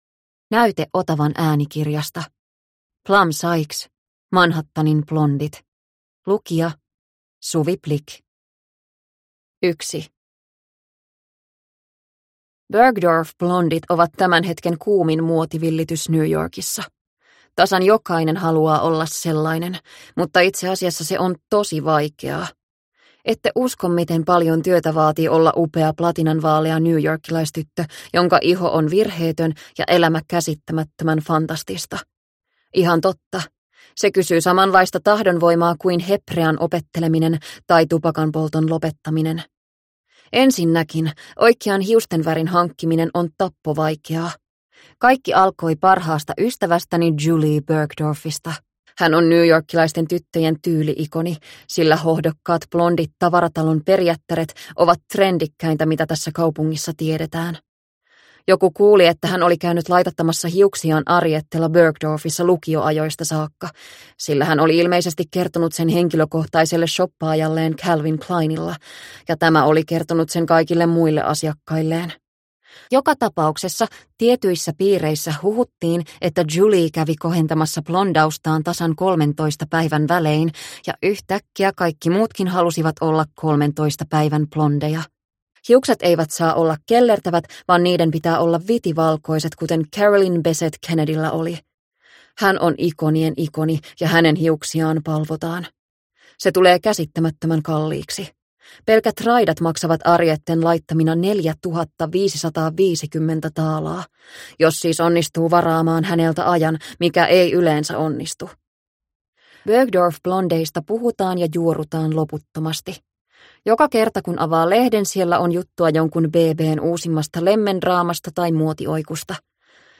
Manhattanin blondit – Ljudbok – Laddas ner